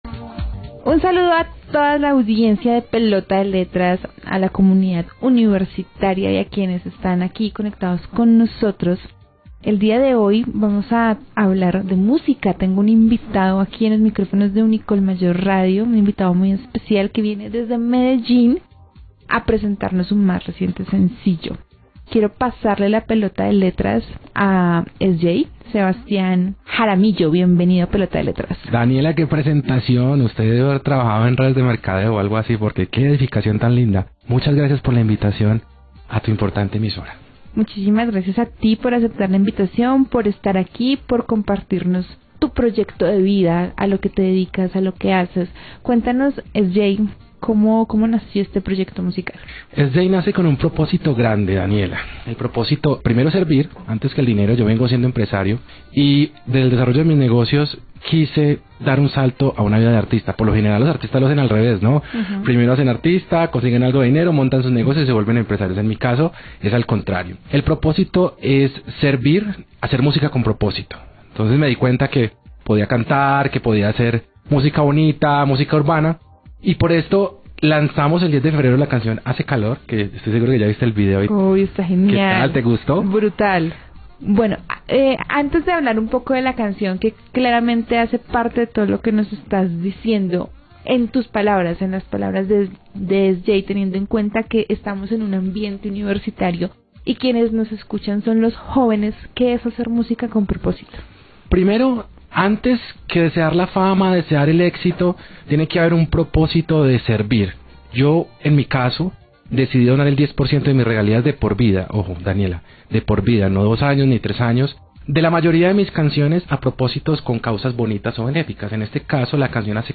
Entrevista para la comunidad Universitaria Unicolmayor de Cundinamarca (Colombia)